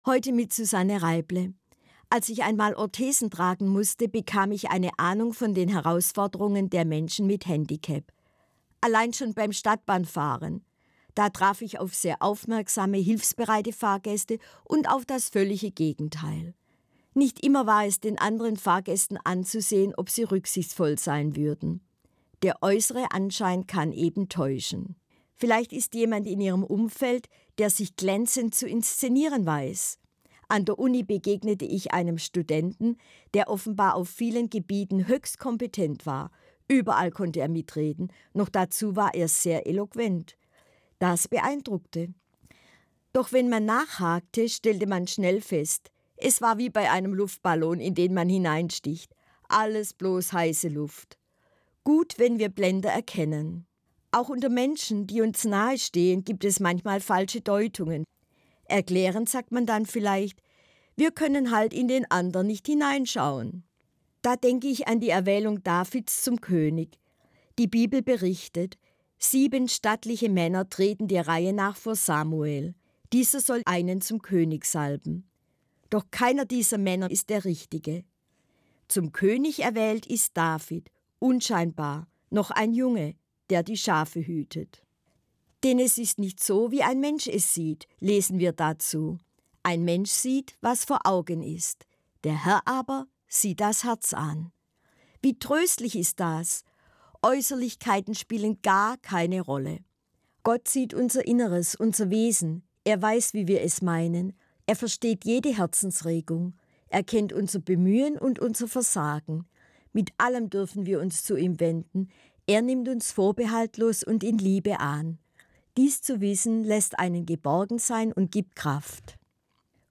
An jedem vierten Sonntag im Monat verantwortet die Gebietskirche Süddeutschland eine Sendung im Hörfunkprogramm des Südwestrundfunks: Sie wird im Magazin aus Religion, Kirche und Gesellschaft „SWR1 Sonntagmorgen“ ausgestrahlt, jeweils um 7:27 Uhr (Verkündigungssendung mit 2,5 Minuten Sendezeit).